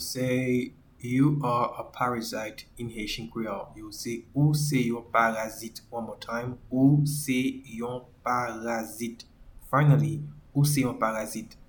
Pronunciation:
You-are-a-parasite-in-Haitian-Creole-Ou-se-yon-parazit.mp3